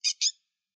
PixelPerfectionCE/assets/minecraft/sounds/mob/rabbit/idle4.ogg at mc116